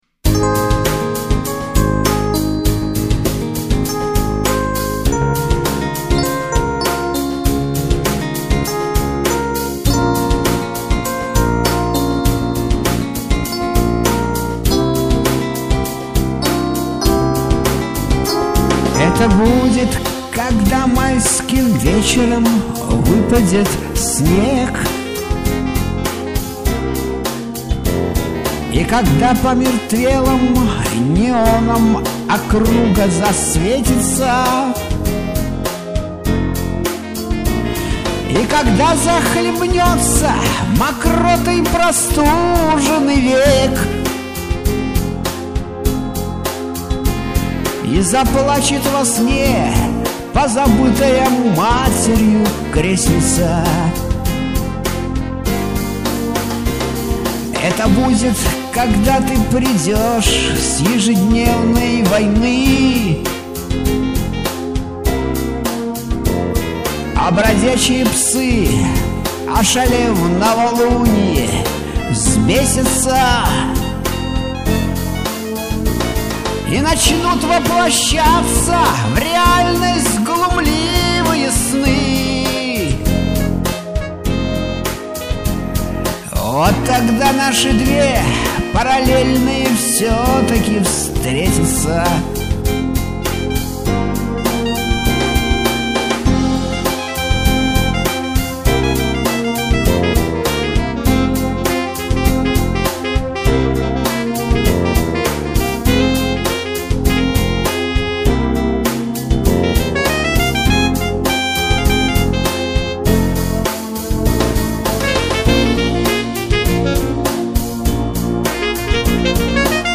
Авторские песни